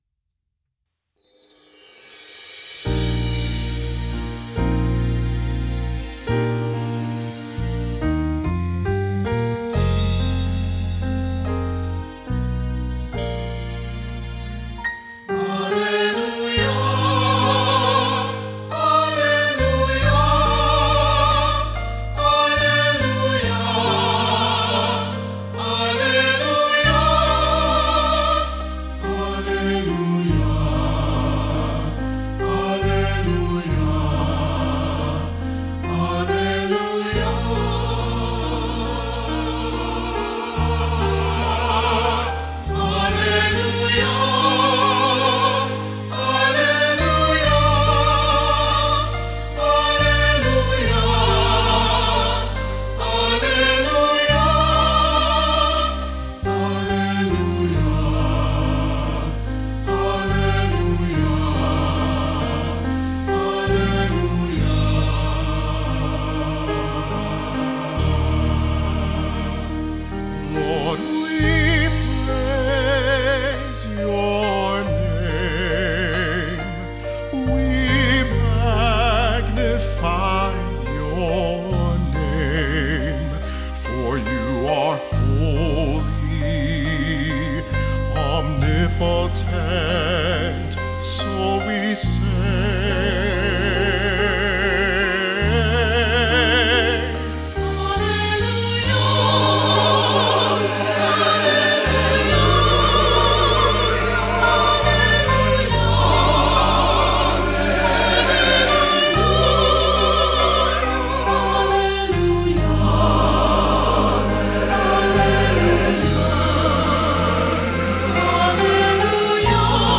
Voicing: "SATB","Solo","Assembly"